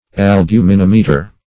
Search Result for " albuminimeter" : The Collaborative International Dictionary of English v.0.48: Albuminimeter \Al*bu`mi*nim"e*ter\, n. [L. albumen, albuminis + -meter: cf. F. albuminim[`e]tre.] An instrument for ascertaining the quantity of albumen in a liquid.